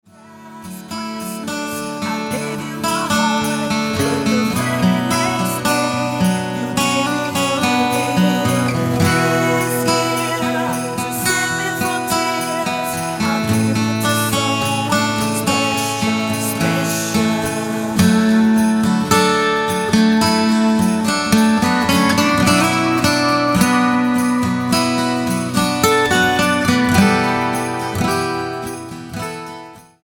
Tonart:D mit Chor